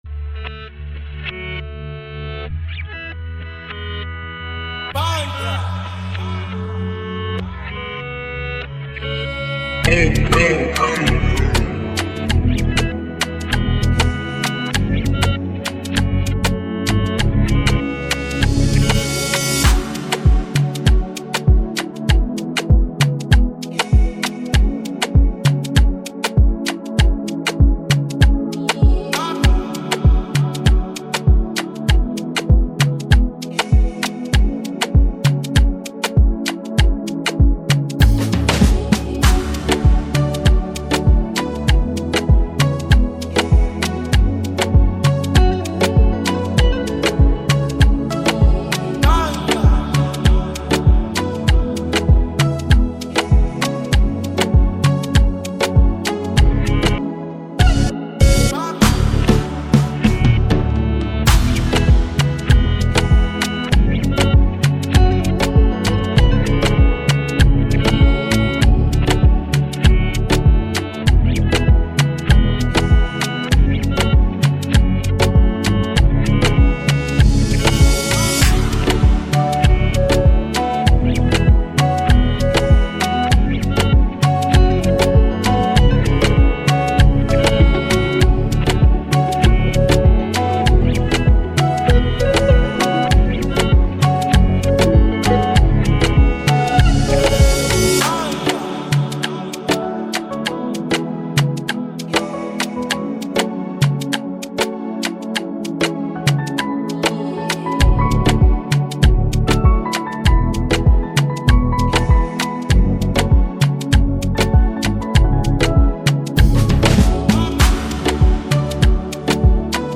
GENRE: Afrobeat (Amapiano)